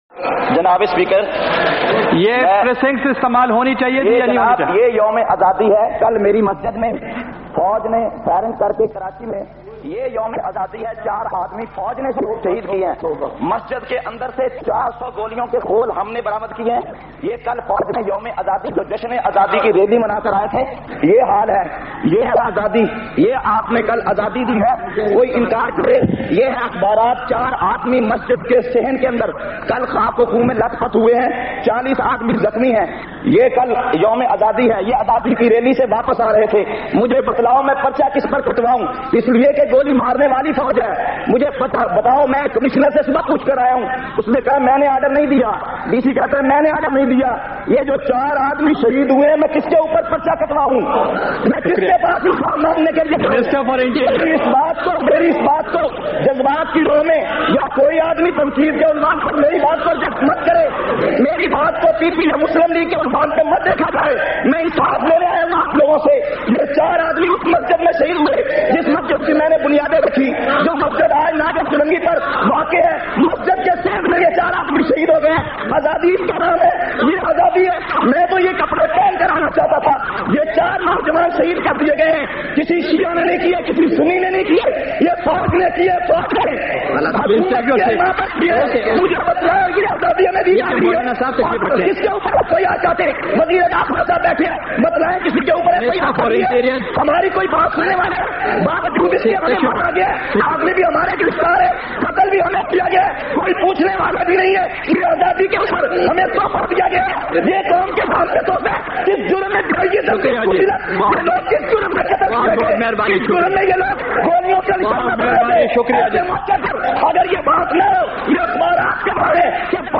452- Qaomi Assembly Khutbat Vol 6.mp3